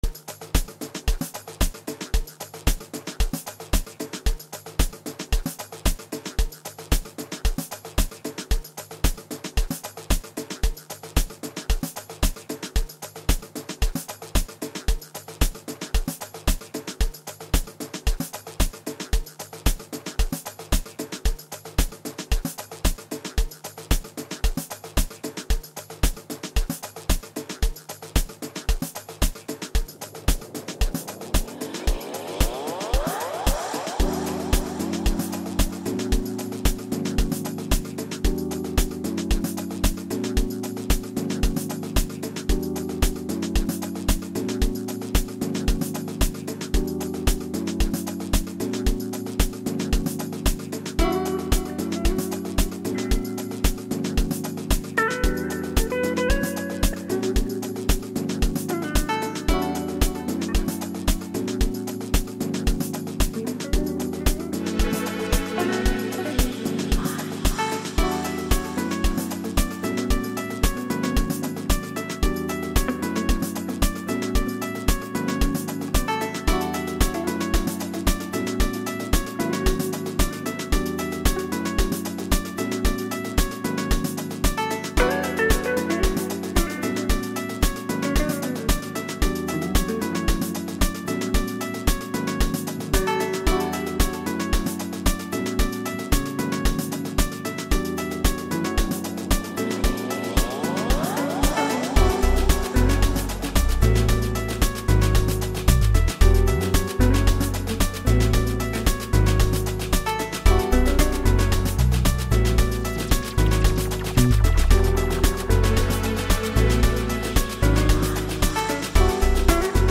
enchanting tune